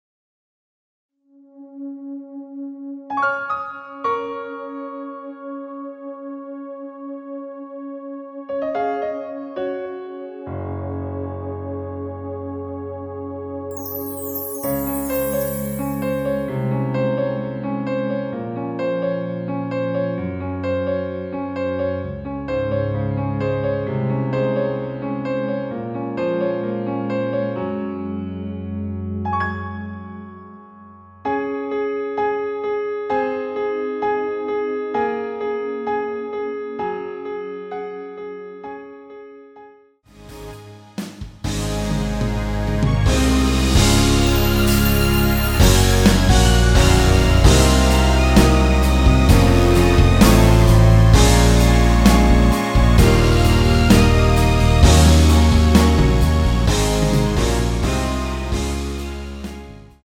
원키에서(-2)내린 MR입니다.
Db
앞부분30초, 뒷부분30초씩 편집해서 올려 드리고 있습니다.